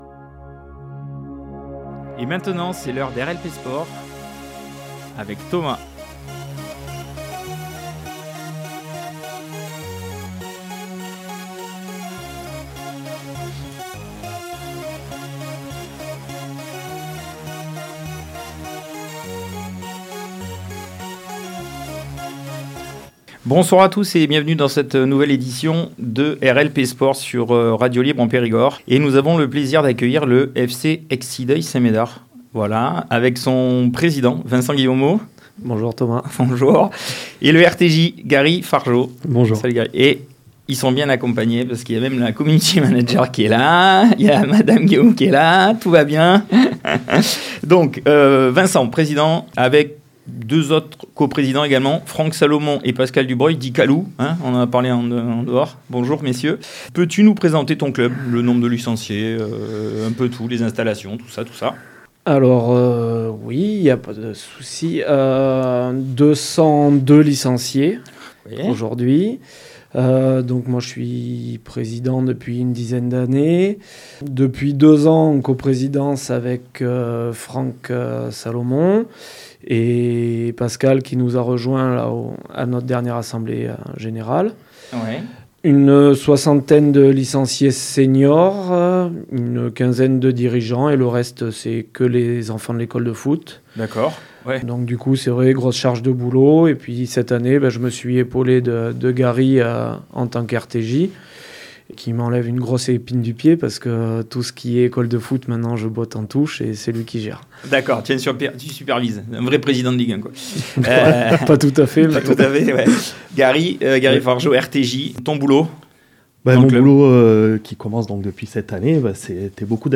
Tous les mardis en direct à 19h et à réécouter tous les vendredis à 9h30.